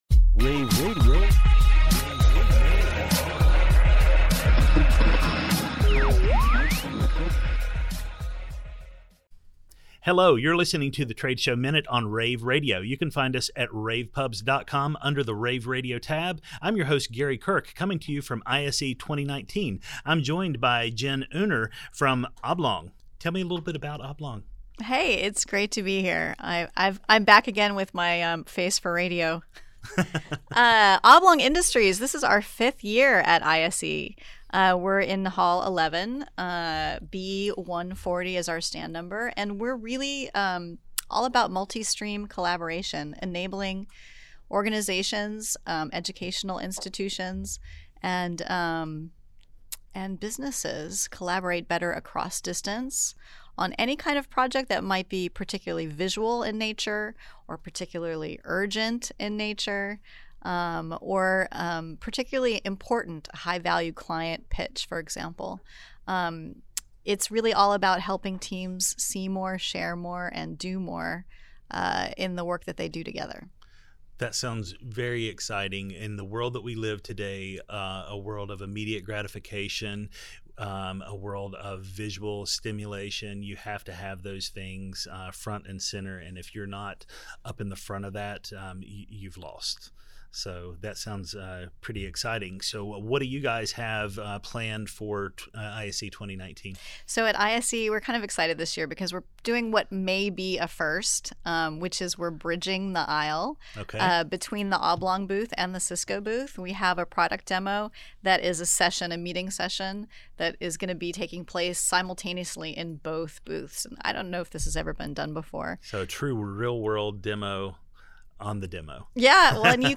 February 5, 2019 - ISE, ISE Radio, Radio, The Trade Show Minute,